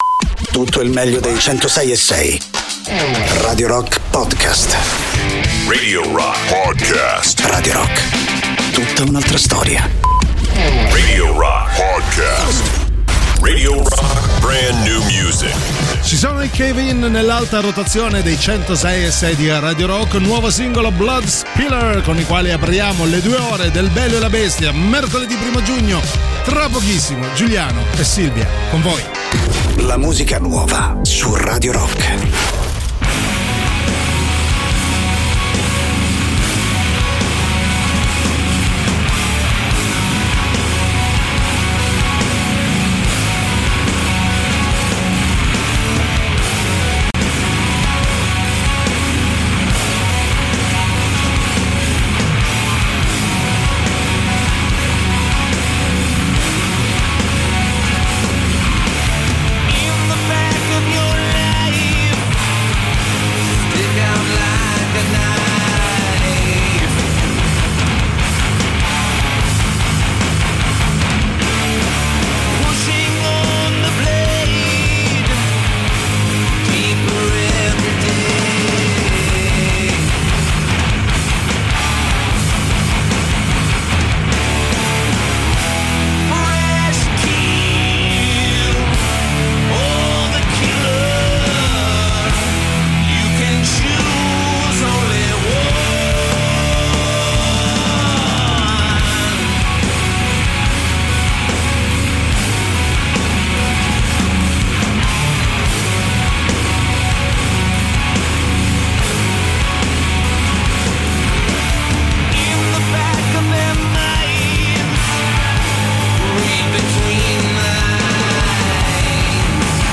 in diretta sui 106.6 di Radio Rock dal Lunedì al Venerdì dalle 13.00 alle 15.00.